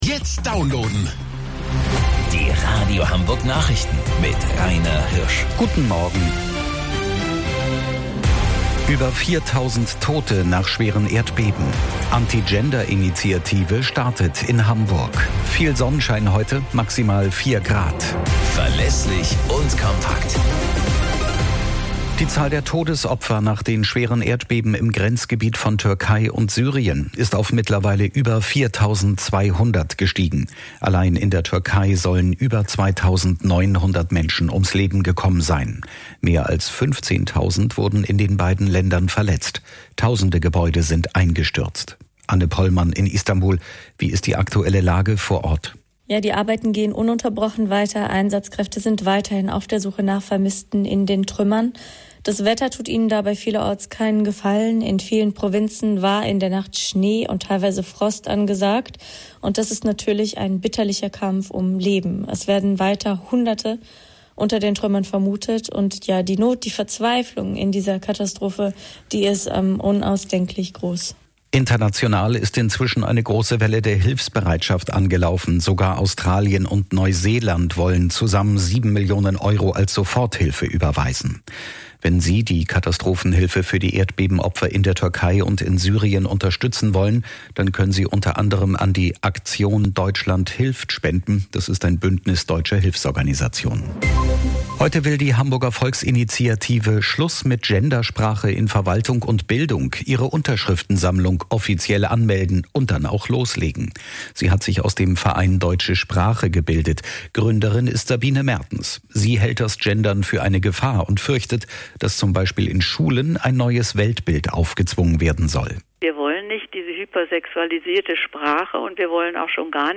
Radio Hamburg Nachrichten vom 11.06.2022 um 13 Uhr - 11.06.2022